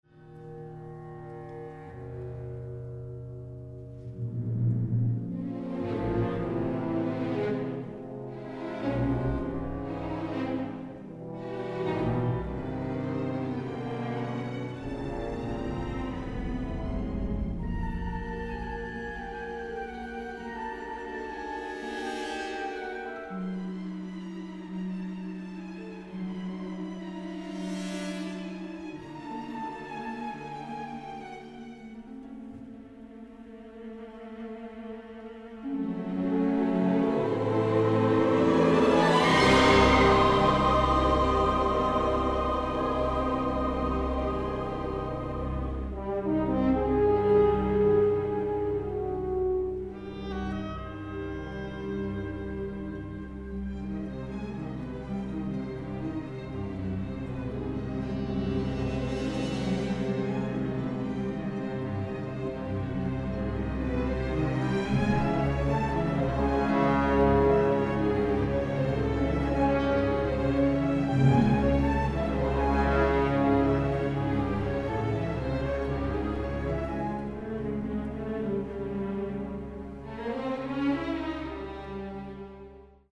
THE FILM SCORE